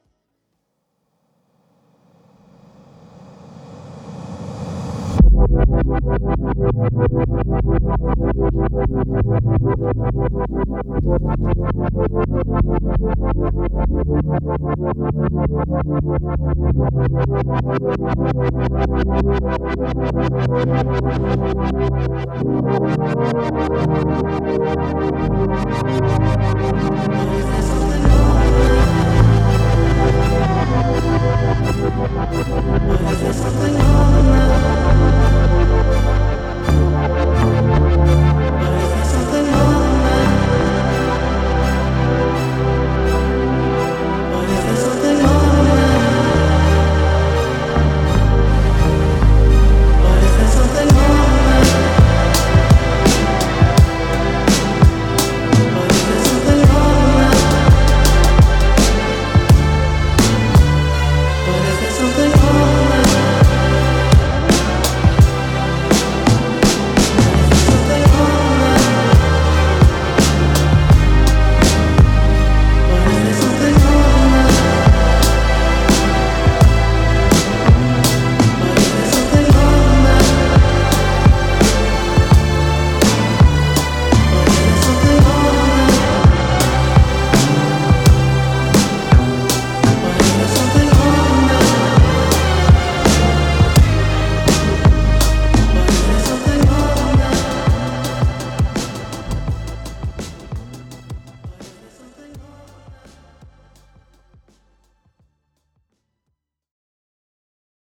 Genre: Indie Rock, Psychedelic